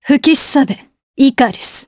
Isolet-Vox_Skill3_jp_b.wav